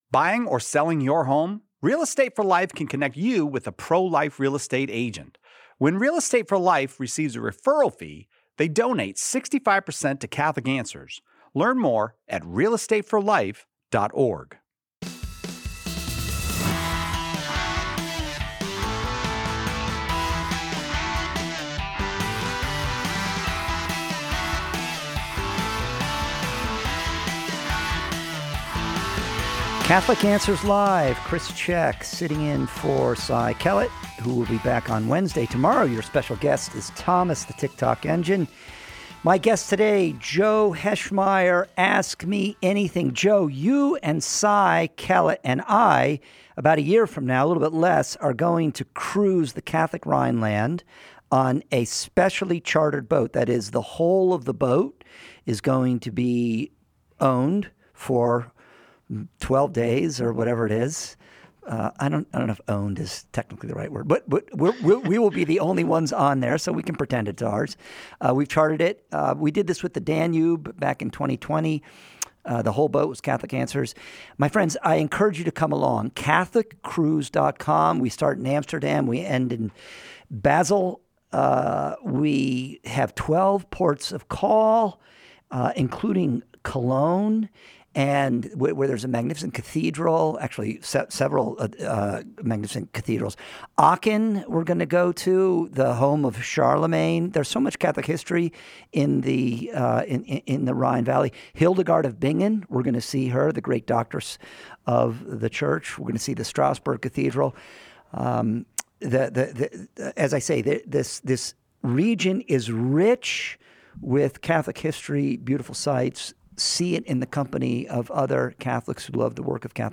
In this episode of Catholic Answers Live , listeners ask thoughtful and wide-ranging questions on Catholic doctrine and spirituality. Topics include whether a cantor’s lifestyle affects the Mass, how to discern mystical experiences from coincidences, and the potential impact of Ecumenical Councils on doctrine and liturgy.